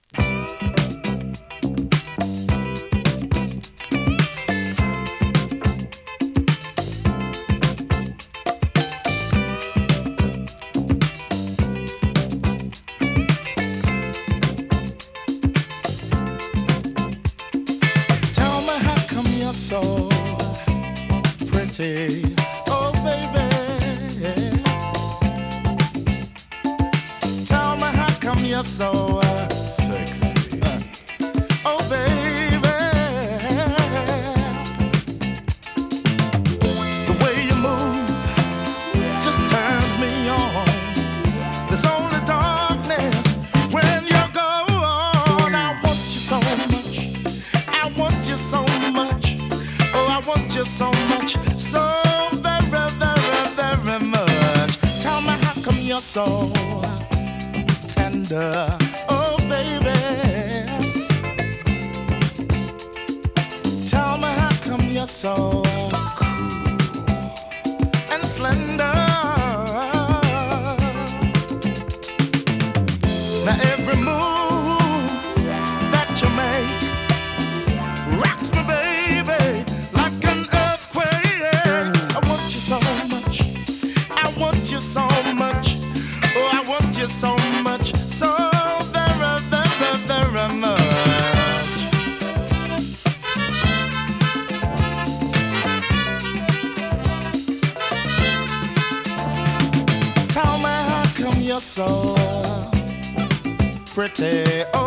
SOUL FUNK 45
風のカッティングと
風なホーンを組み合わせた爽快ミディアムの
[2track 7inch]＊音の薄い部分でチリチリ・ノイズ。